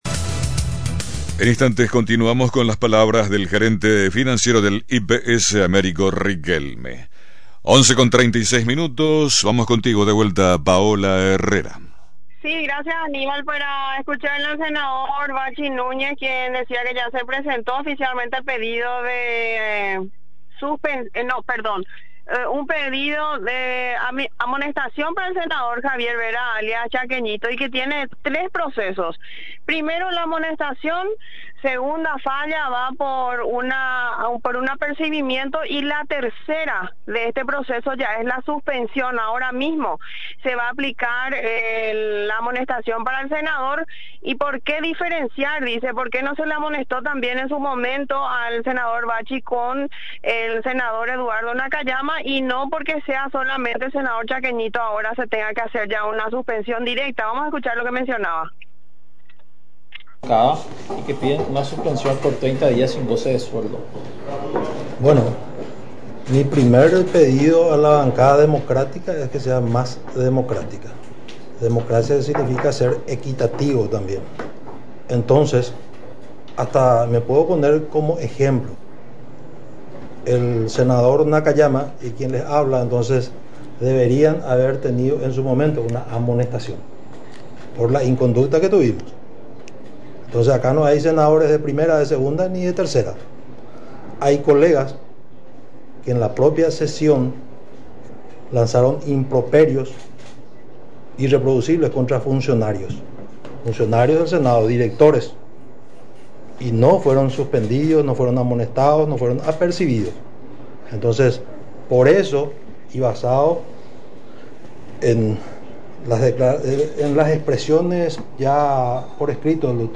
En rueda de prensa, junto al titular de Dinavisa, Jorge Illou, se procedió a la presentación del proyecto de Ley para la unificación de ambas dependencias del Estado.